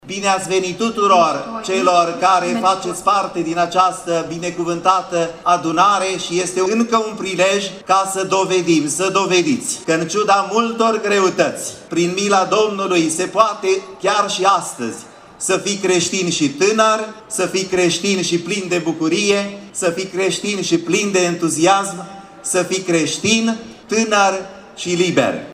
Peste şase mii de adolescenţi din 24 de ţări din Europa, Africa, America şi Orientul Apropiat participă, la Iaşi, timp de patru zile, la cea de-a patra ediţie a Întâlnirii Internaţionale a Tinerilor Ortodocşi.
La rândul său, Înalt Prea Sfinţitul Teofan, mitropolitul Moldovei şi Bucovinei, a urat bun venit participanţilor la eveniment.